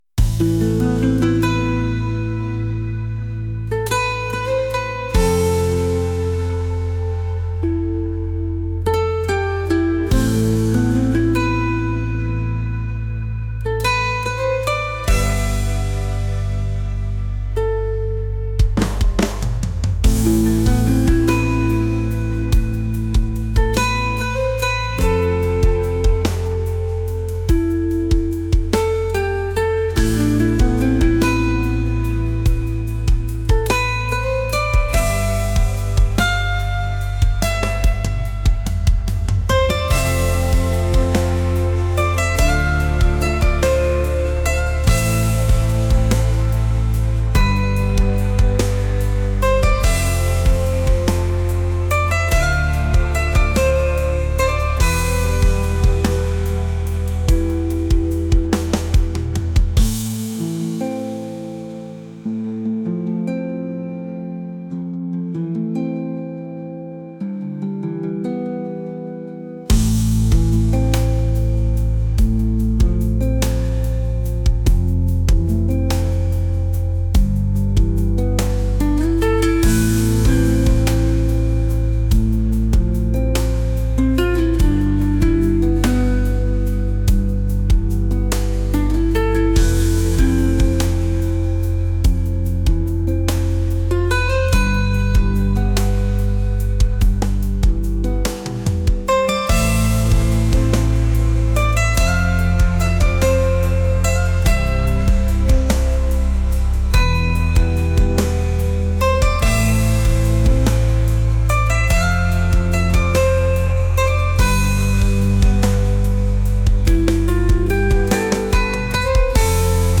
atmospheric | fusion | world